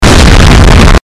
Sound Buttons: Sound Buttons View : EXPLOSAO DADA
exploes.mp3